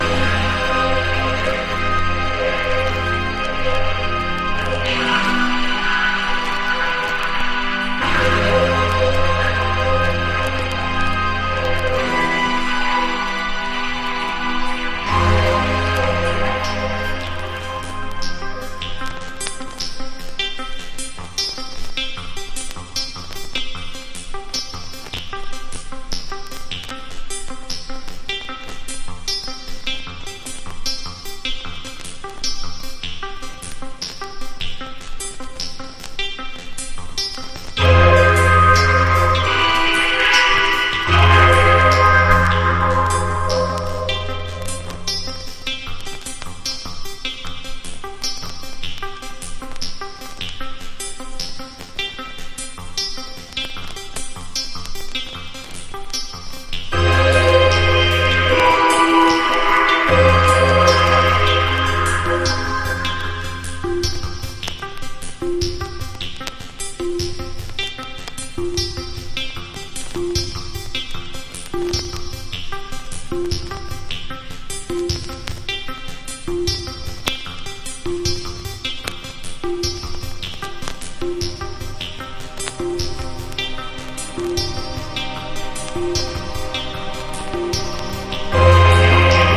メカニカルなビートにパーカッション、チェロが絡み、無機質な中にもロマンティックな音世界を創出した作品！
PROGRESSIVE# AMBIENT / EXPERIMENTAL